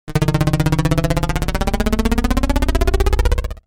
دانلود صدای هلیکوپتر 7 از ساعد نیوز با لینک مستقیم و کیفیت بالا
جلوه های صوتی